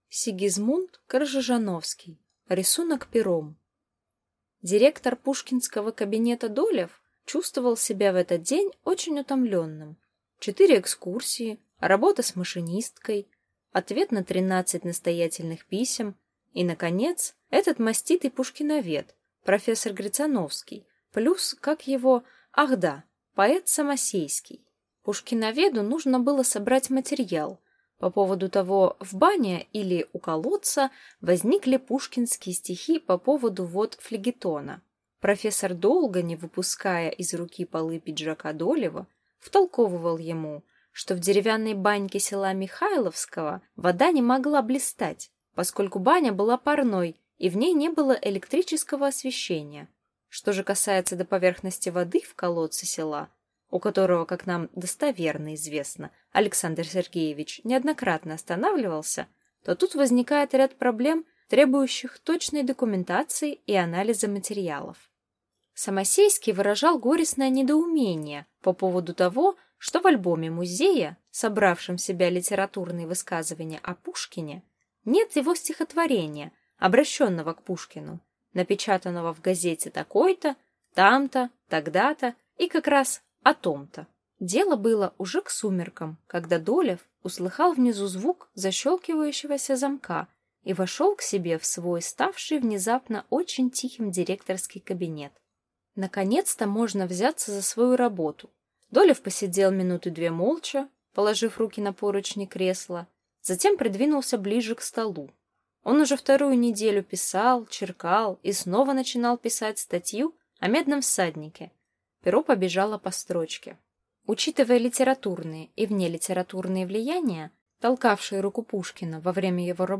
Аудиокнига Рисунок пером | Библиотека аудиокниг